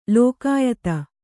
♪ lōkāyata